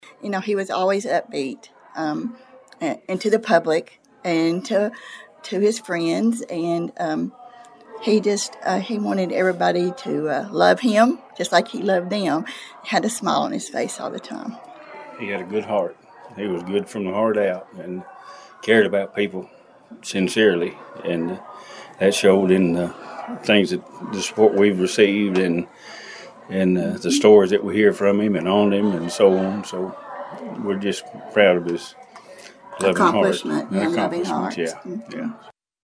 In an interview with WPKY’s News Edge prior to the ceremony